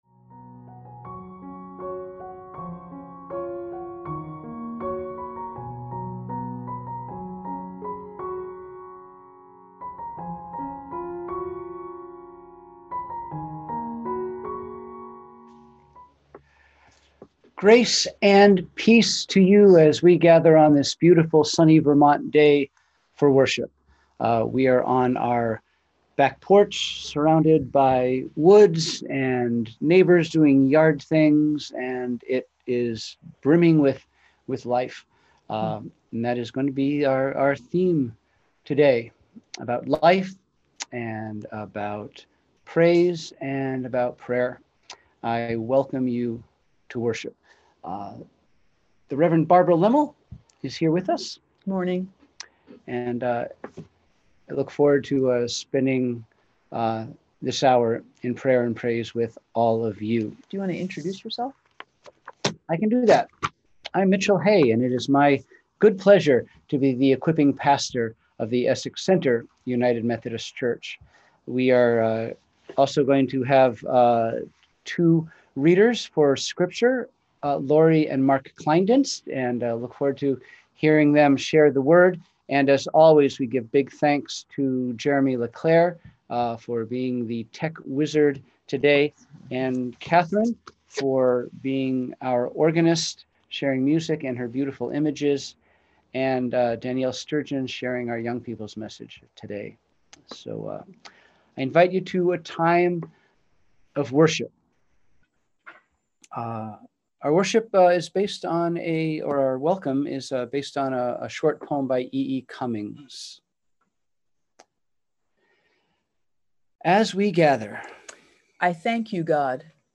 We held virtual worship on Sunday, August 9, 2020 at 10am.